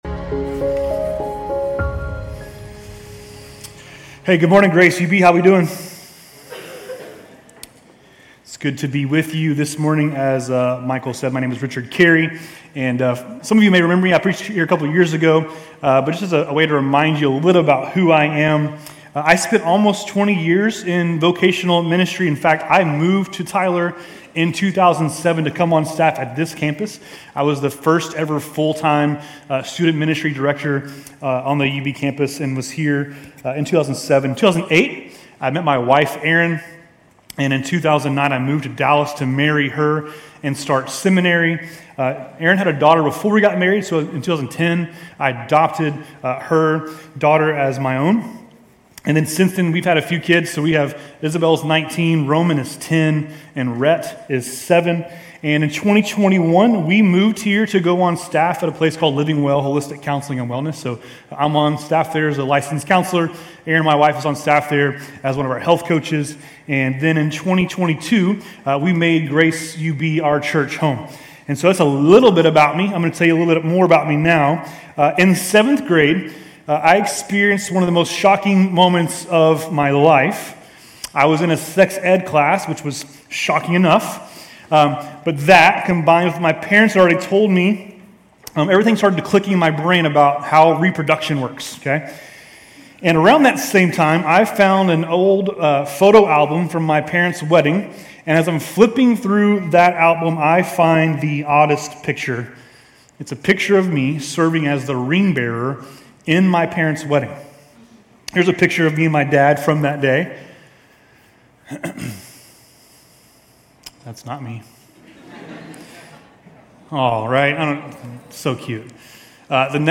Grace Community Church University Blvd Campus Sermons Galatians 4:8-31 May 20 2024 | 00:29:53 1x 00:00 / 00:29:53 Subscribe Share RSS Feed Share Link Embed